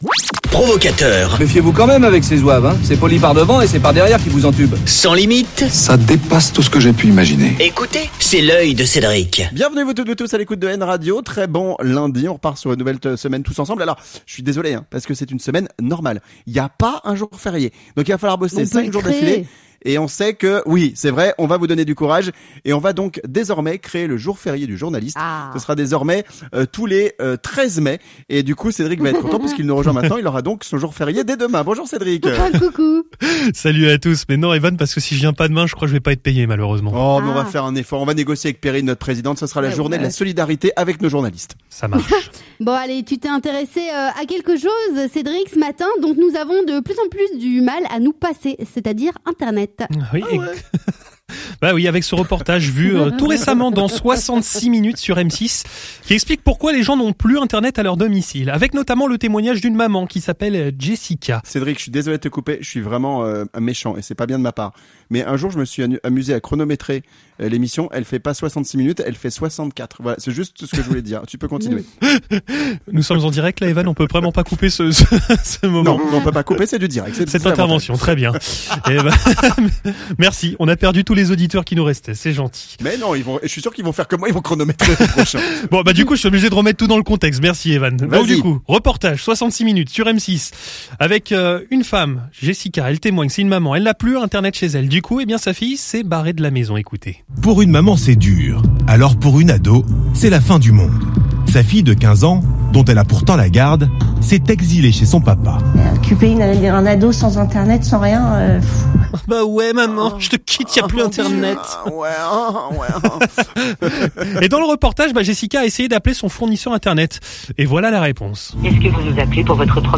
Reportage dans un quartier victime de pannes internet...